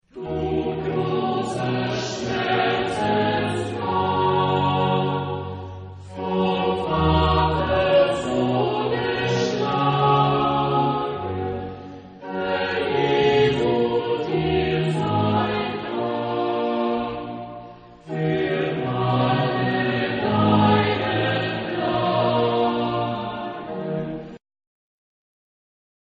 Genre-Style-Form: Chorale ; Sacred
Type of Choir: SATB  (4 mixed voices )
Instruments: Organ (ad lib)
Tonality: E minor